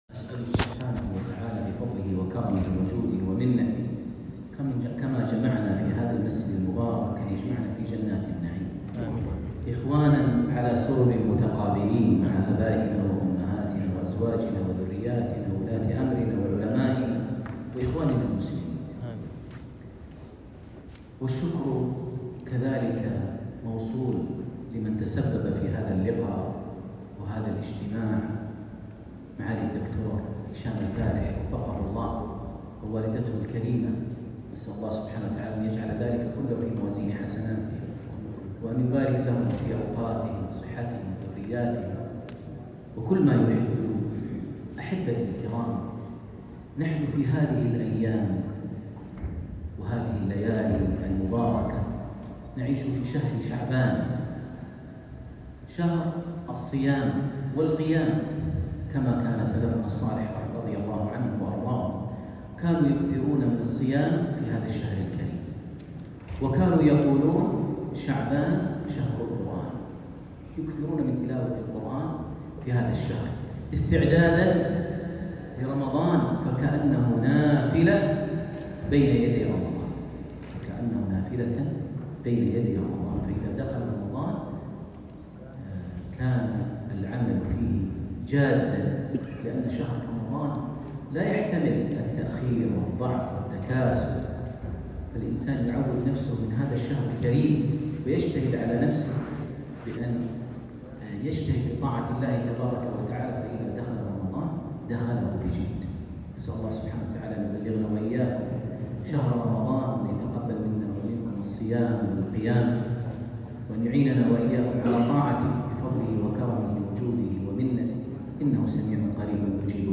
كلمة للشيخ ماهر المعيقلي بعد صلاة العشاء في الرياض - جامع حصة الفارس | الإثنين ١٤ شعبان ١٤٤٧هـ > إمامة الشيخ ماهر المعيقلي وجهوده الدعوية داخل السعودية > تلاوات و جهود الشيخ ماهر المعيقلي > المزيد - تلاوات الحرمين